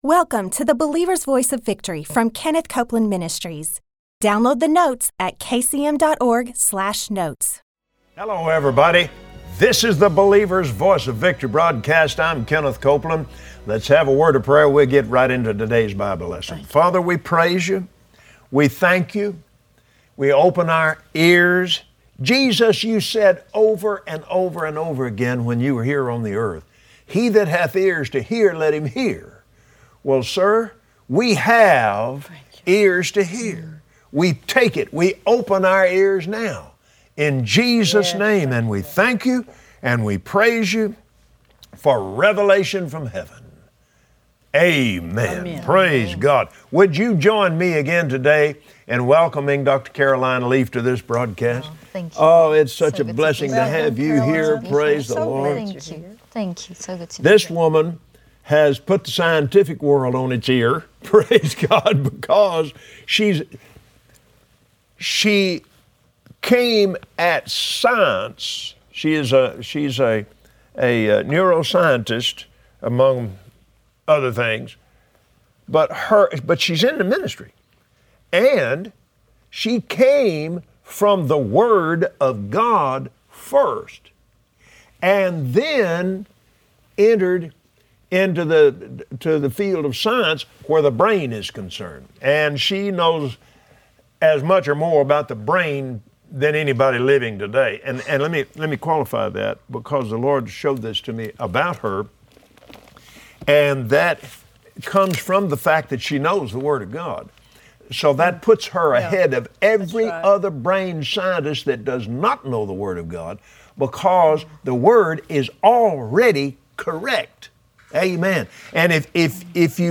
Believers Voice of Victory Audio Broadcast for Tuesday 08/25/2015 Today, on the Believer’s Voice of Victory, Kenneth and Gloria Copeland welcome their special guest, Doctor Caroline Leaf.